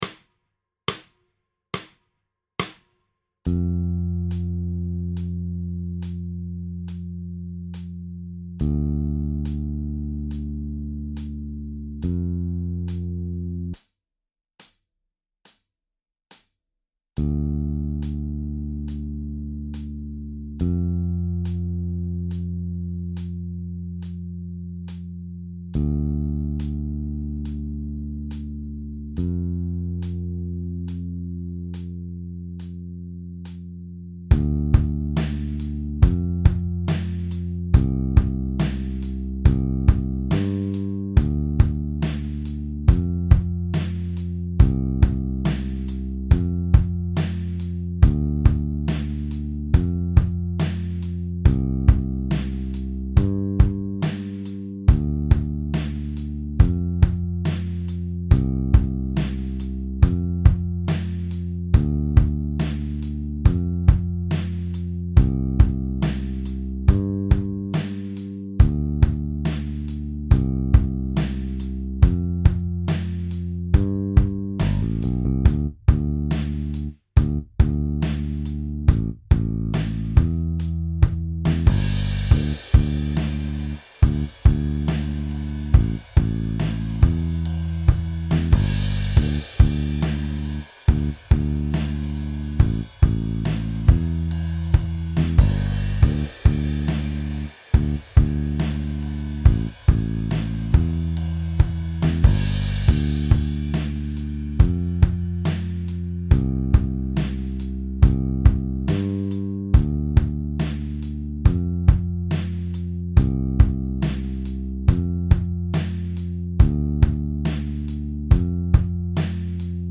This lesson is complete with jam track and TAB.